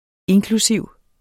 Udtale [ ˈenkluˌsiwˀ ]